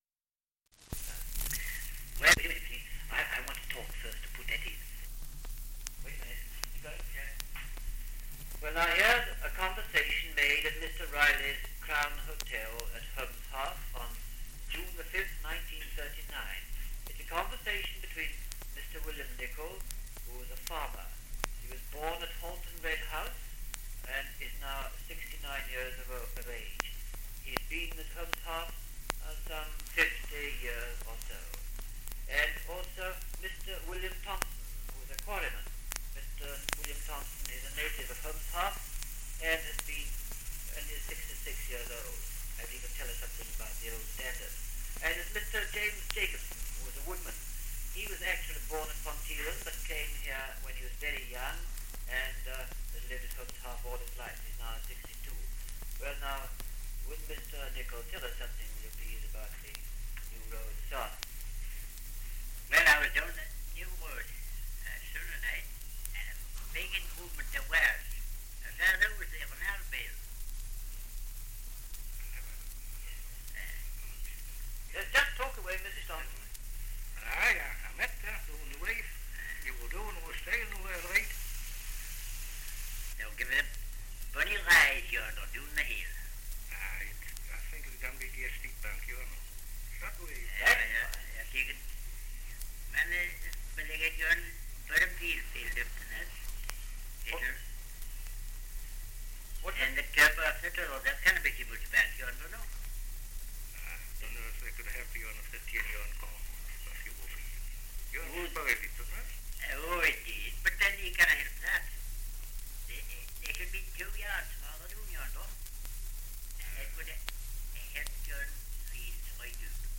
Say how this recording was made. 78 r.p.m., cellulose nitrate on aluminium